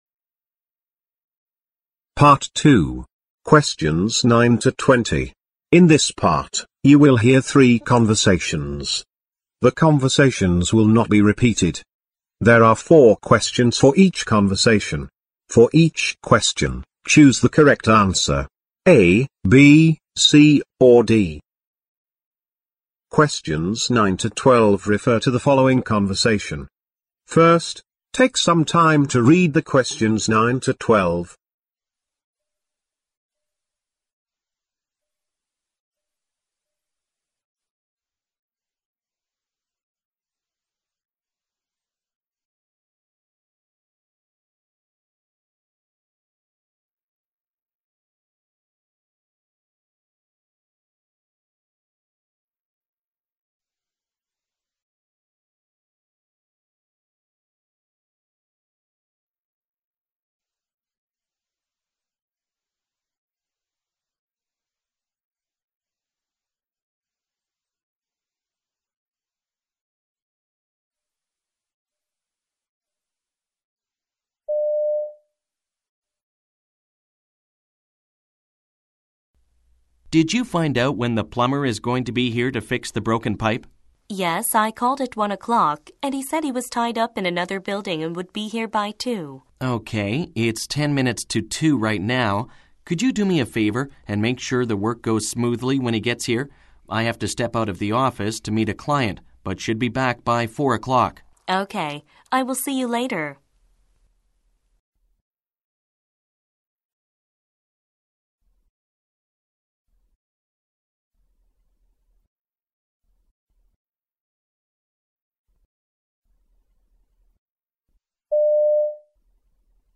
Directions: In this part, you will hear THREE conversations.